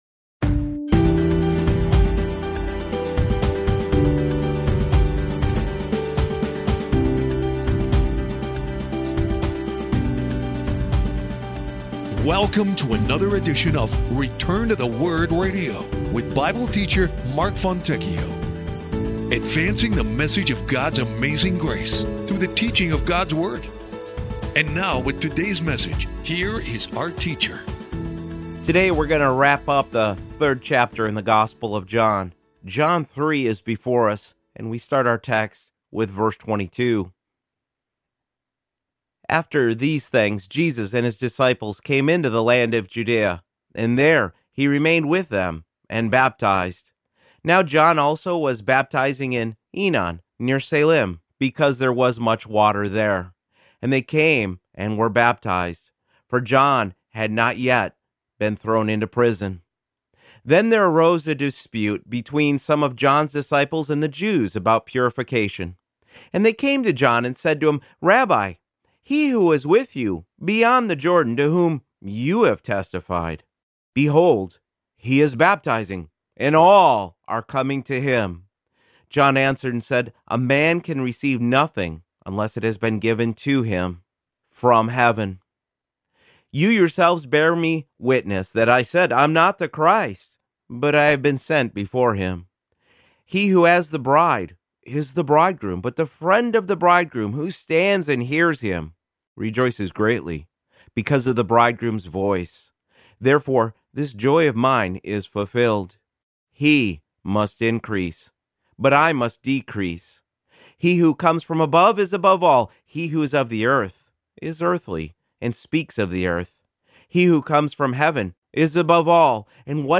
Teaching God's Word and advancing the message of His amazing grace one verse at a time.